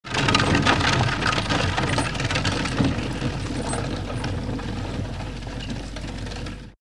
На этой странице собраны натуральные звуки телег и повозок: скрип деревянных колес, цоканье копыт лошадей, шум движения по грунтовой дороге или брусчатке.
Лошадь с телегой гуляет под звук копыт